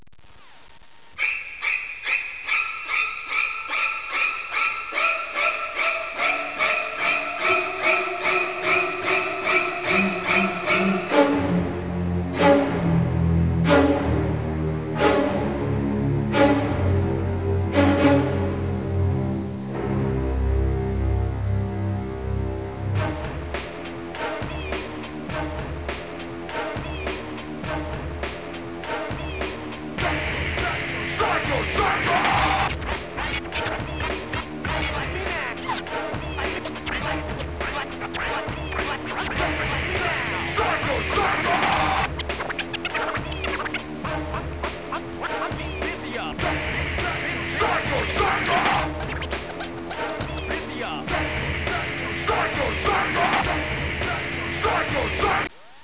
Bass
Drums
Guitar
Vocals